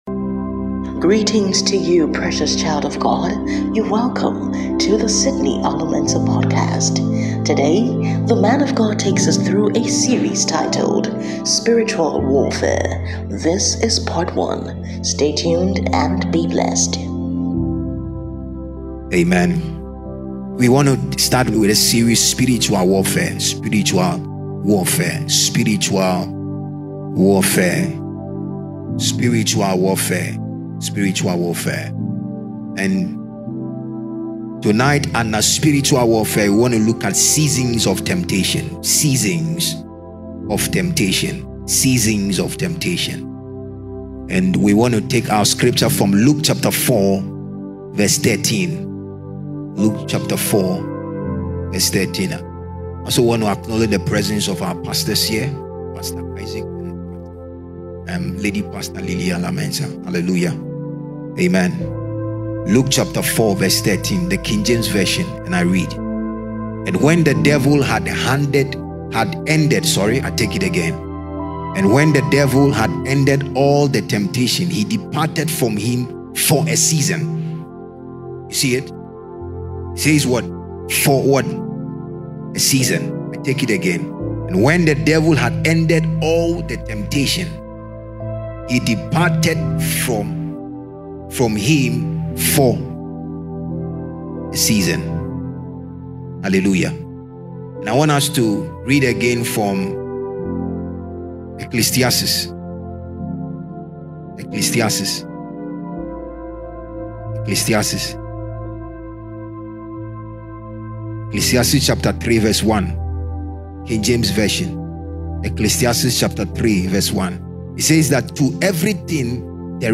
Preached at GLCI – City of His Presence, Madina Estates, Accra, Ghana on 8th January 2025.